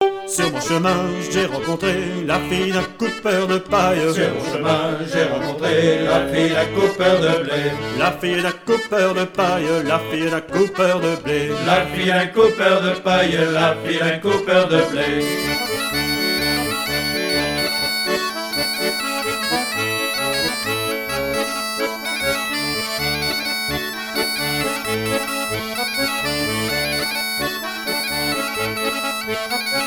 danse : branle
Pièce musicale éditée